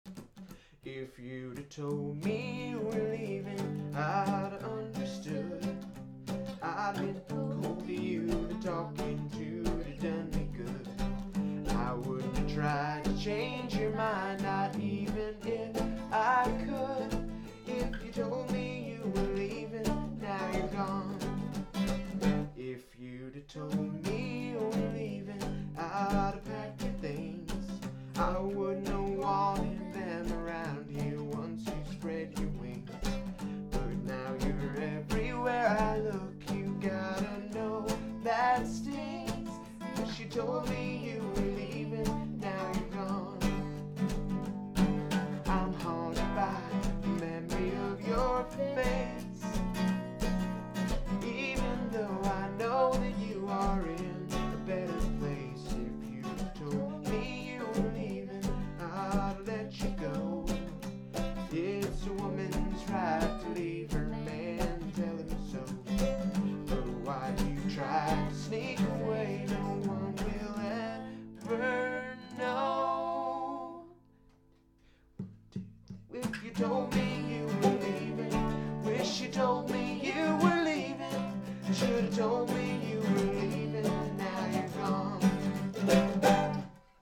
in G, easy
A A B A'